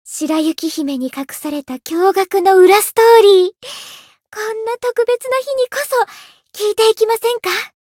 灵魂潮汐-爱莉莎-情人节（相伴语音）.ogg